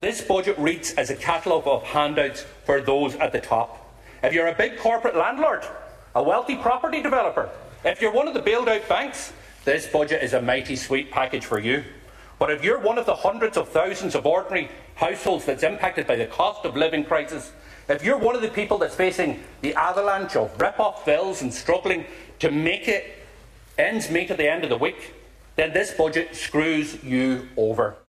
Sinn Féin’s Finance Spokesperson Pearse Doherty says the Finance and Public Expenditure Ministers’ speeches were “dripping with arrogance” and offered nothing for the ordinary households: